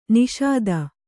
♪ niṣada